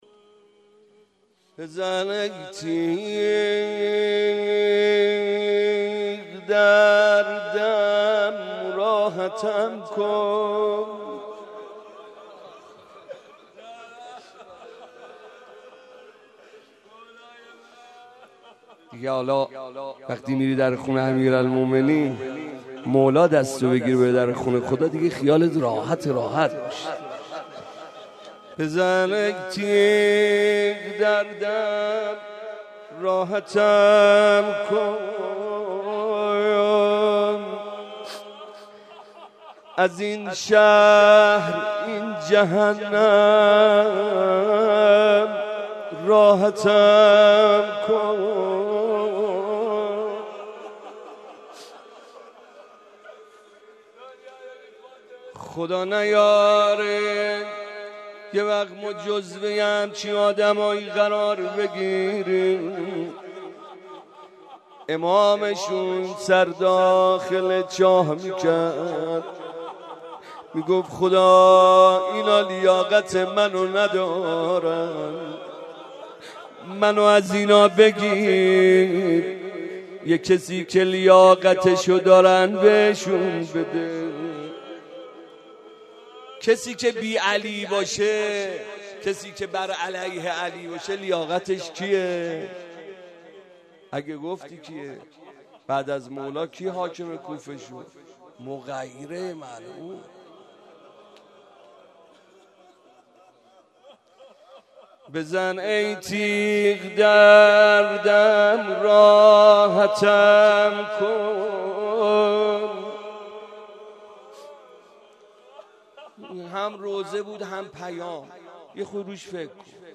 03.rozeh.mp3